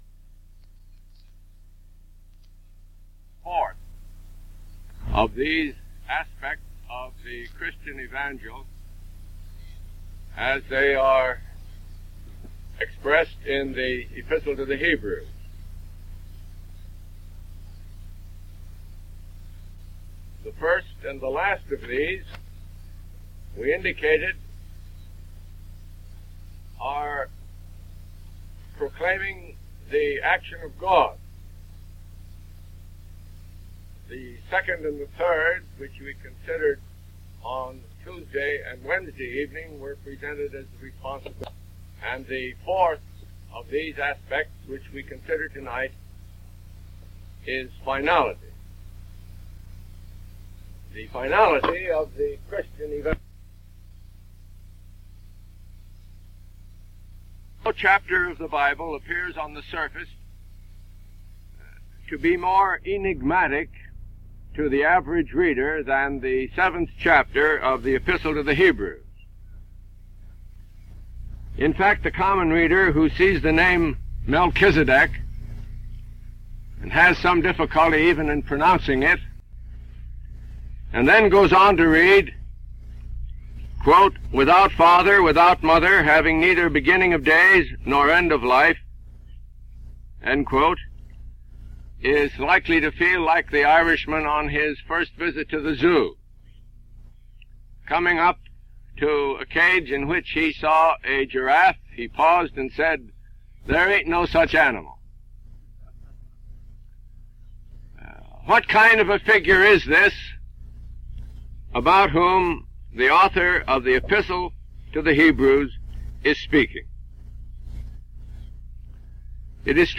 Lectures on Book of Hebrews